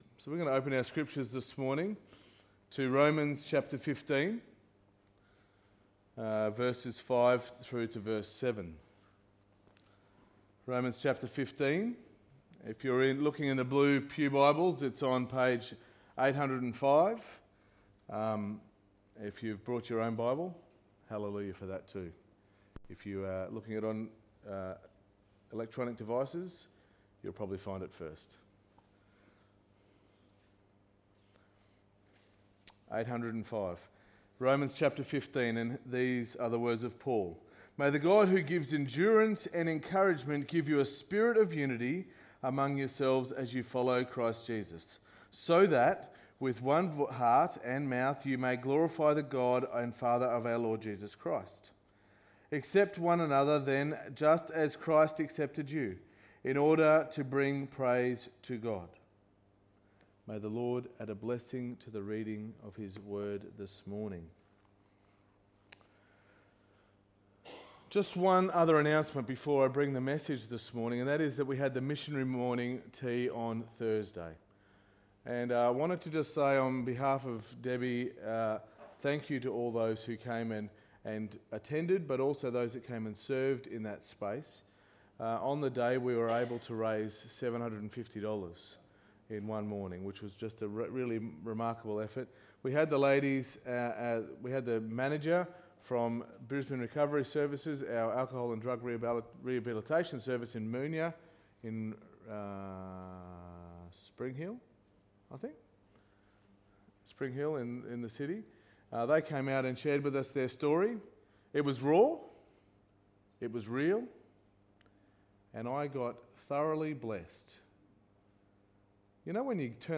SERMON 27.10.2019